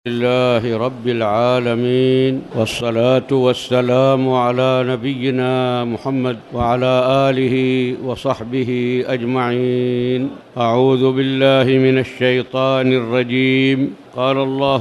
سورة النساء لم يسجل إلا دقيقة
المكان: المسجد الحرام